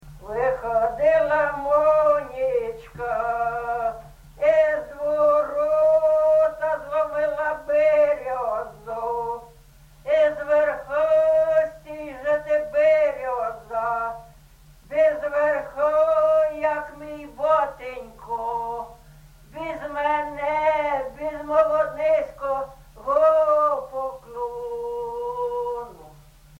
ЖанрВесільні
Місце записус. Софіївка, Краматорський район, Донецька обл., Україна, Слобожанщина